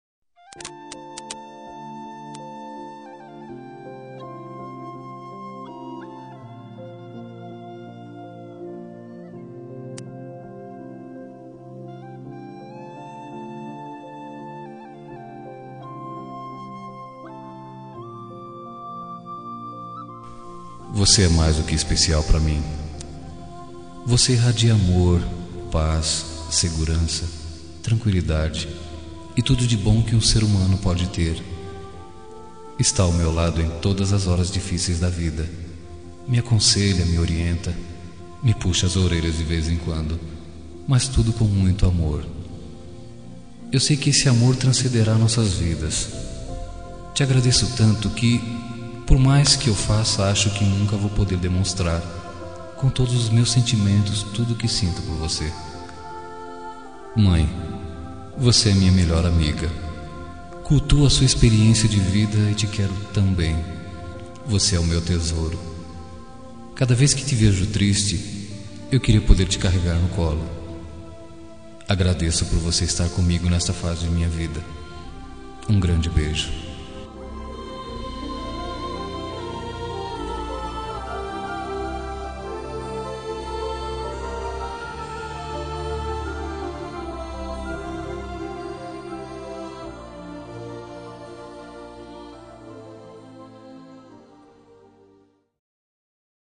Homenagem para Mãe – Voz Masculino – Cód: 320